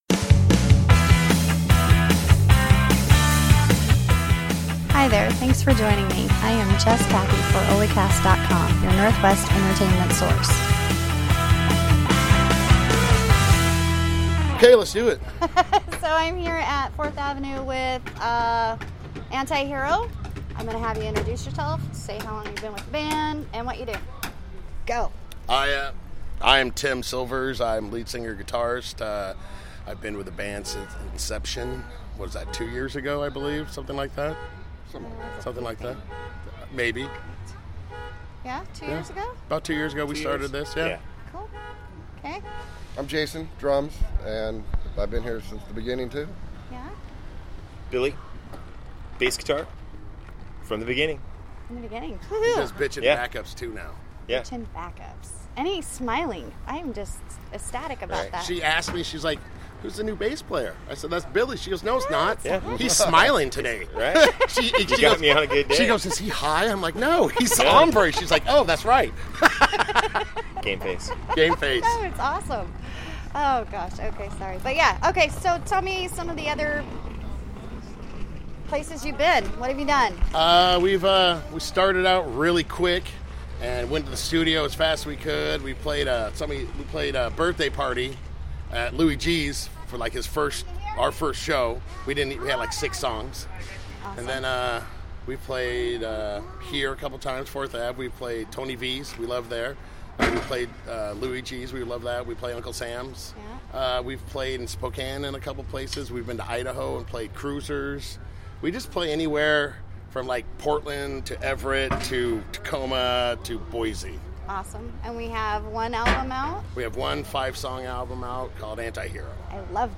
I really enjoyed getting to know them better and lots of laughs (as usual), thank you so much for taking the time.